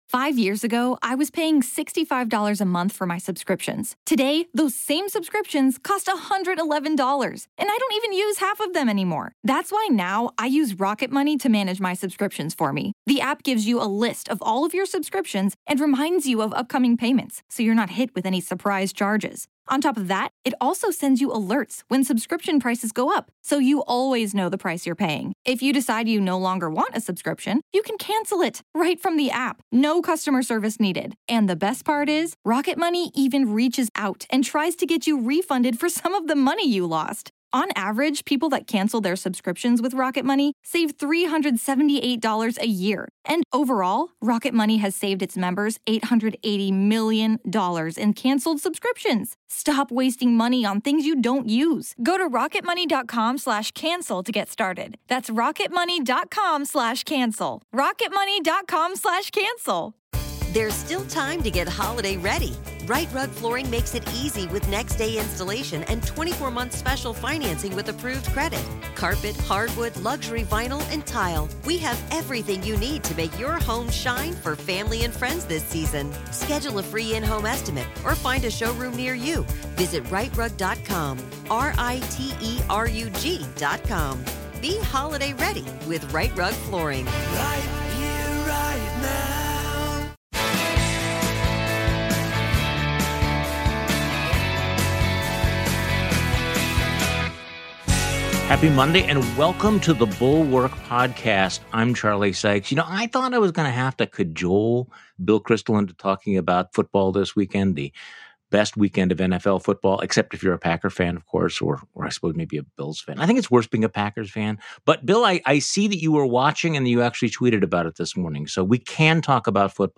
Newt Gingrich's recklessness with partisanship was a precursor of where we are today, and now Trump's followers, like Steve Bannon and Ron DeSantis, are trying to one-up Trump. Bill Kristol joins Charlie Sykes on today's podcast. Special Guest: Bill Kristol.